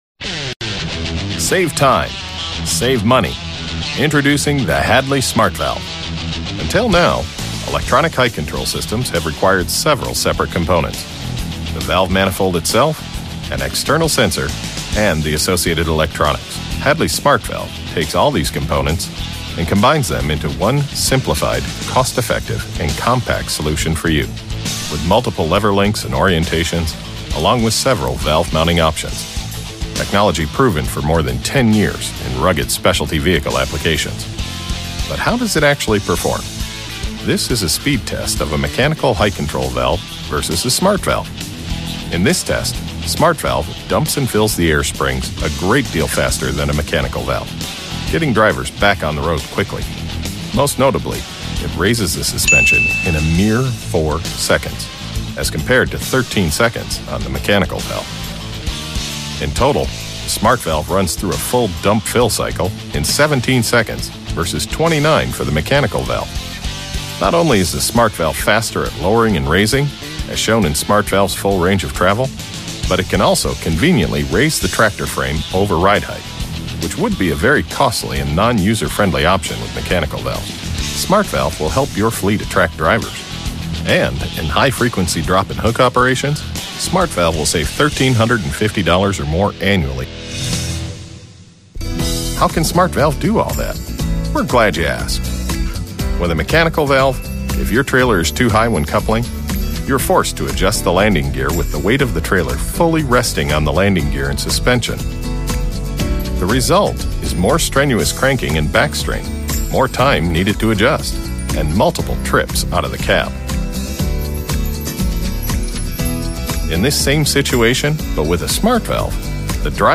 Corporate for Hadley
Middle Aged